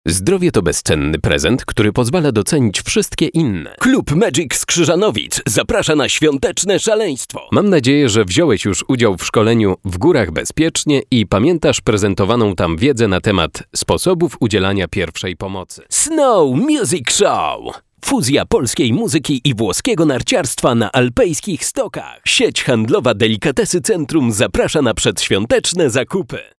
polnischer Sprecher für Werbung, Industrie, Imagefilme
Sprechprobe: eLearning (Muttersprache):
polish voice over talent